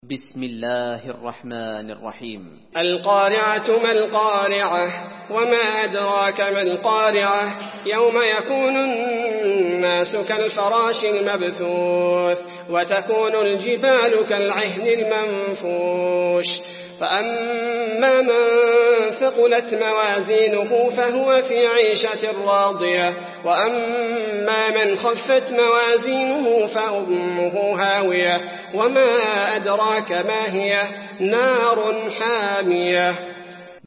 المكان: المسجد النبوي القارعة